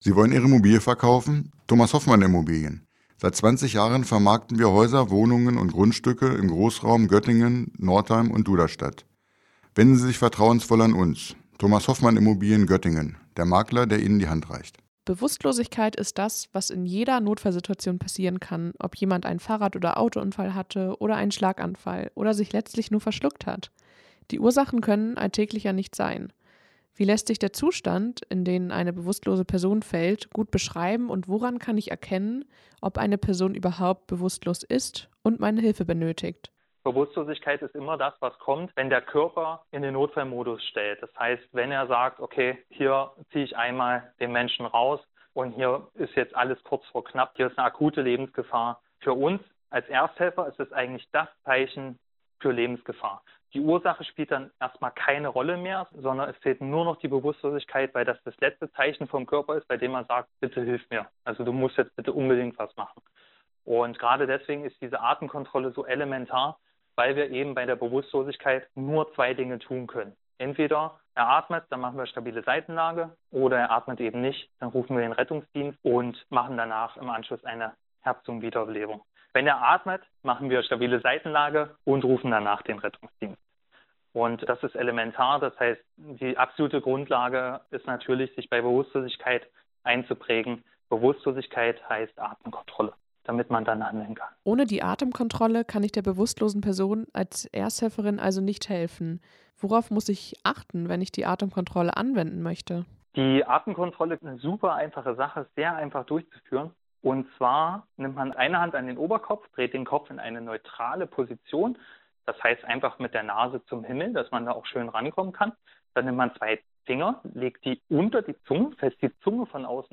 Wie führe ich eine Atemkontrolle durch, wenn ich eine bewusstlose Person auffinde? Und woher weiß ich überhaupt, ob die Person bewusstlos ist? In einem Interview der Serie "Alltägliche Notfallsituationen